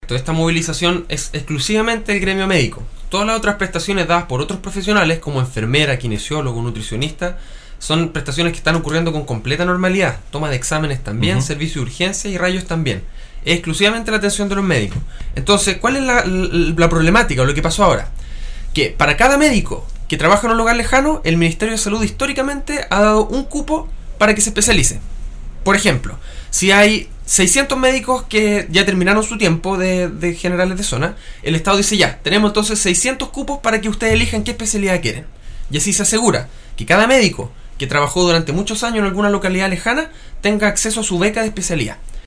Señaló en entrevista con radio Estrella del Mar de Palena que la denominada estrategia de médicos generales de zona tiene un muy positivo impacto en atención de las poblaciones más aisladas y remotas del territorio nacional.